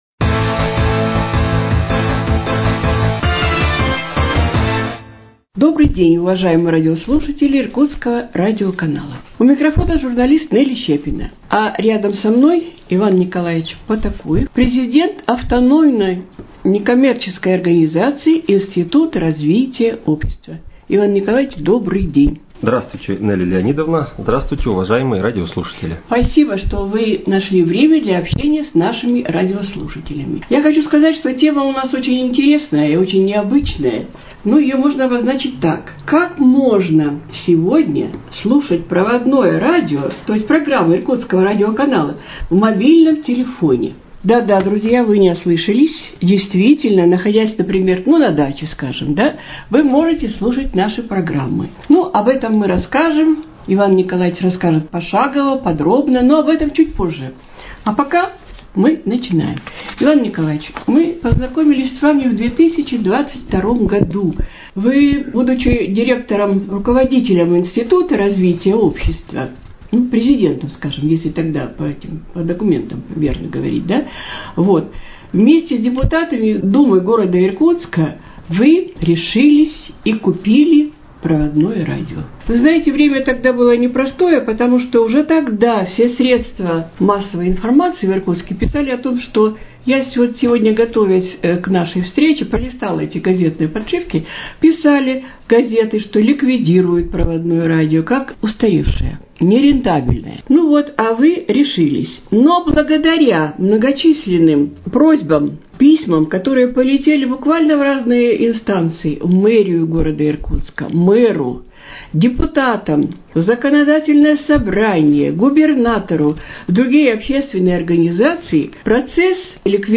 Актуальное интервью: О новых формах развития радиовещания в Иркутске